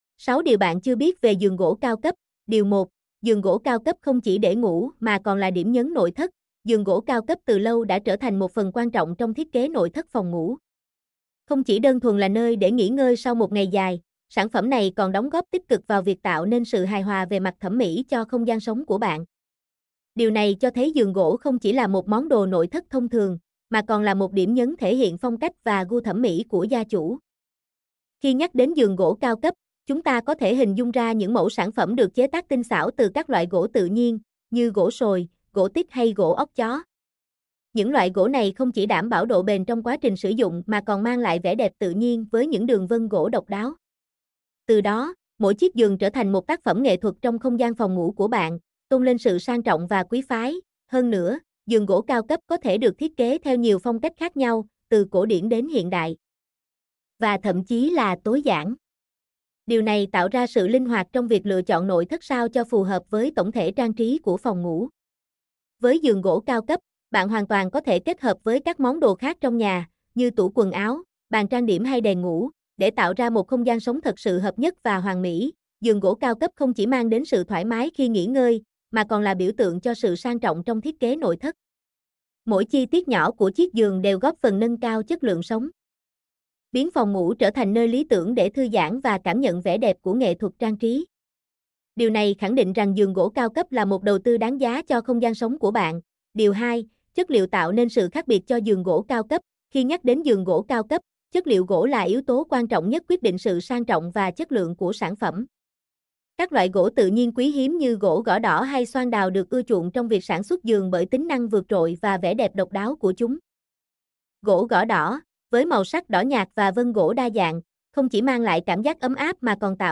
mp3-output-ttsfreedotcom-10.mp3